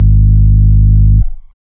17SYN.BASS.wav